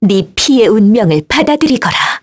Lewsia_B-Vox_Skill6_kr.wav